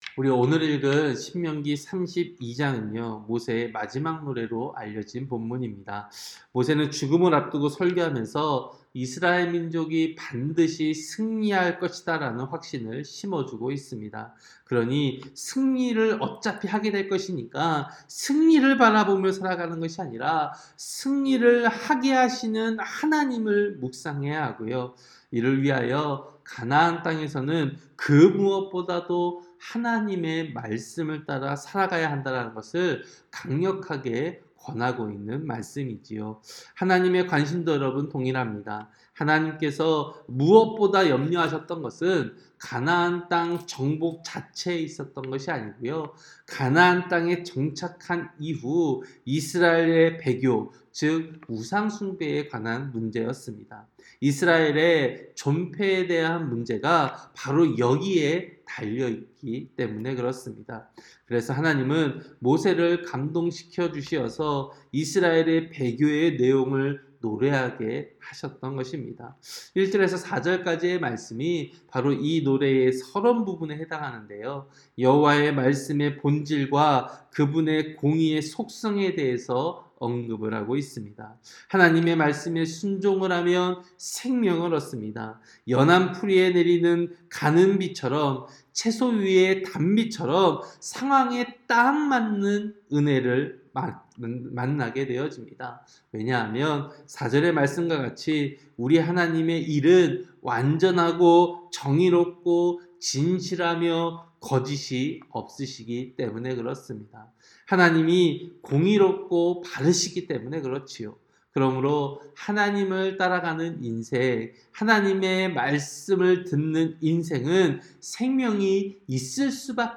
새벽설교-신명기 32장